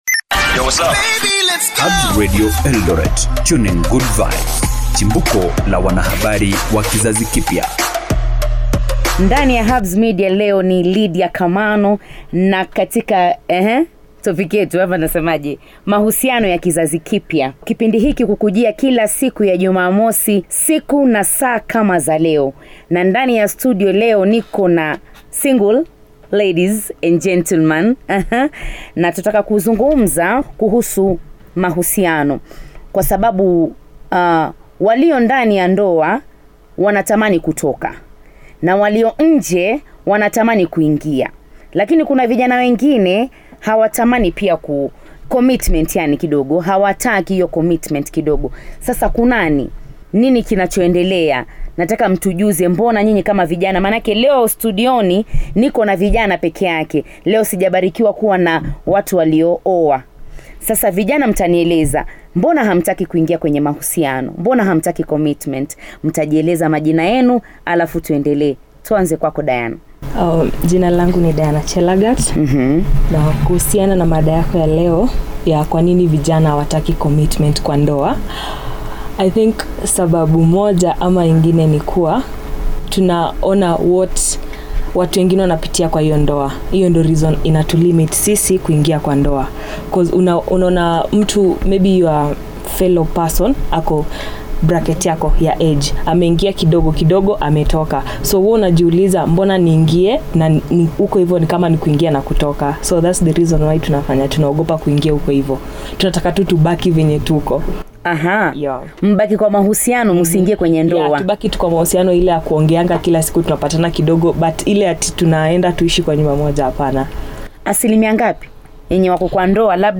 Kwenye mahojiano haya vijana pia wanadadisi sababu za ongezeko la mahusiano ya jinsia moja....